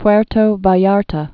(pwĕrtō vä-yärtə, -tä)